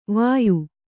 ♪ vāyu